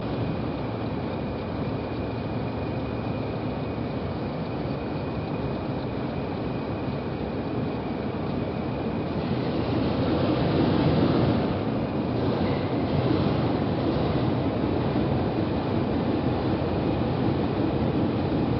20.117 completes fuelling and moves out of No. 1 Shed Road to the North
end exit to the Depot. As 20.117 passes, 40.136, lying outside the Shed in
No. 2 Road, starts up. Motherwell, 16.2.77
Stereo MP3 128kb file